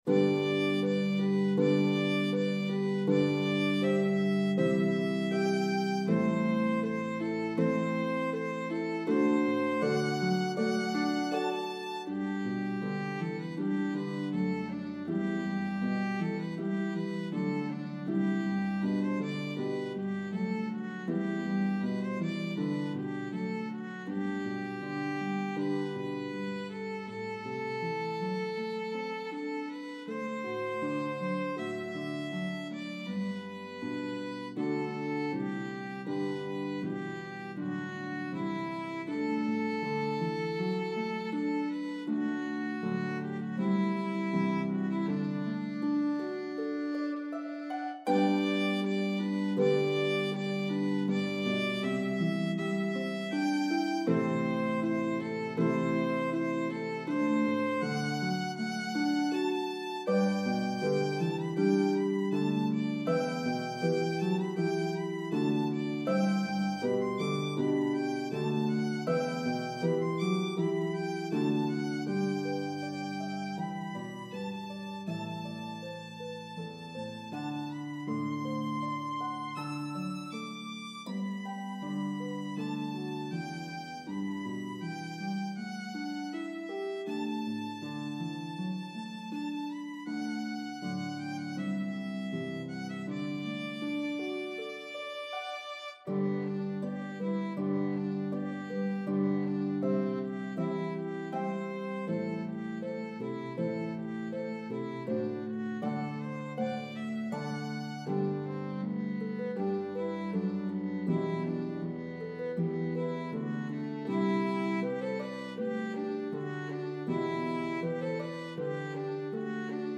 Dynamics are clearly marked.